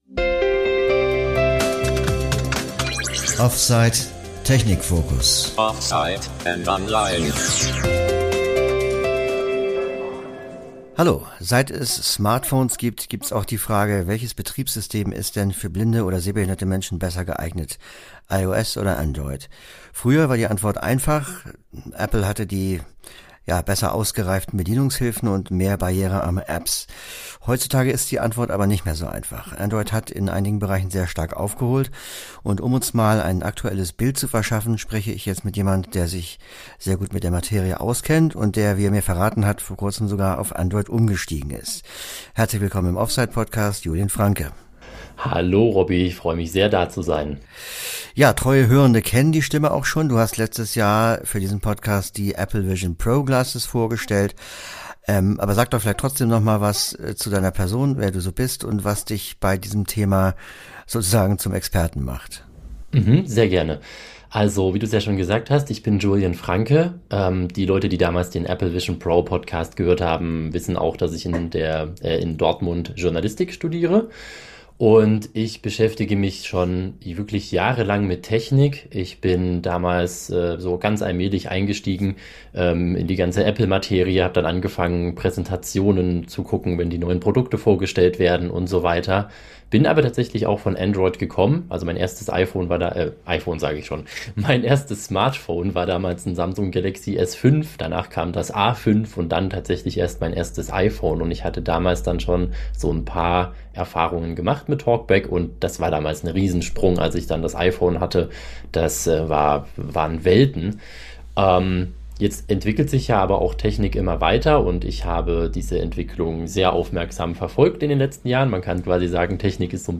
Aktuelle Bestandsaufnahme der Pros und Kontras beider Systeme. Indepth-Gespräch